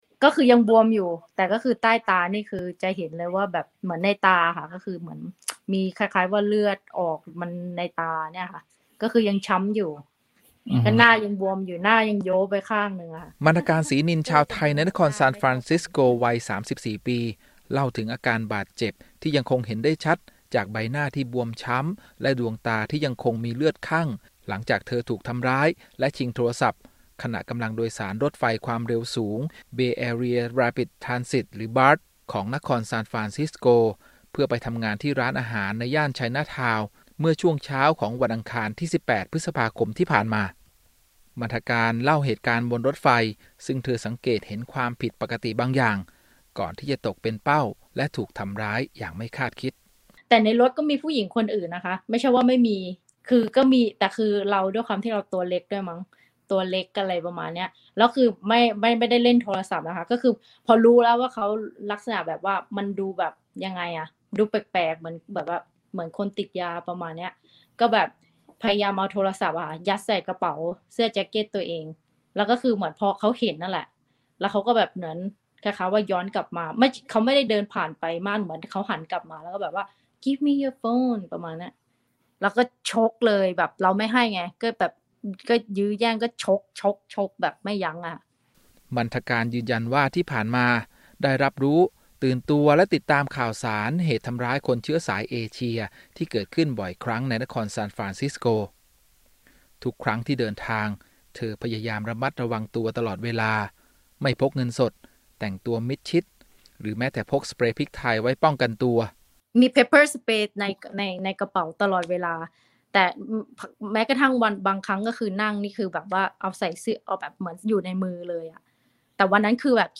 Exclusive Interview a Thai Woman Attacked in San Francisco